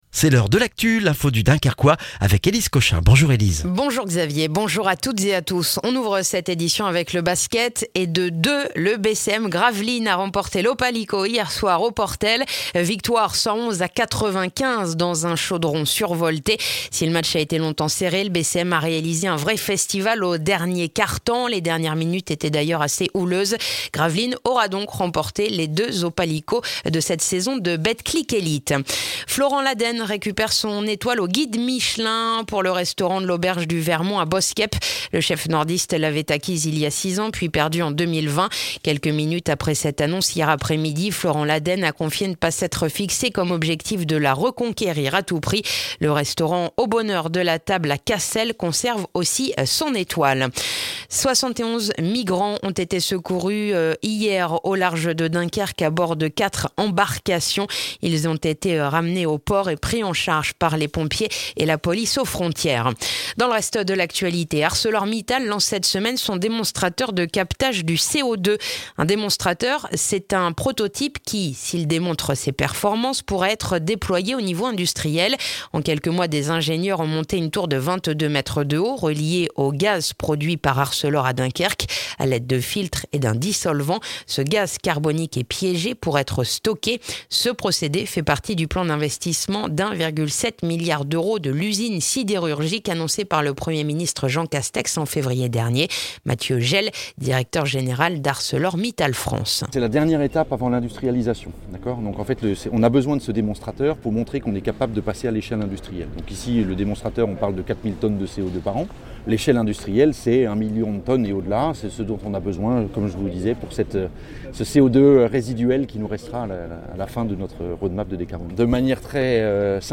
Le journal du mercredi 23 mars dans le dunkerquois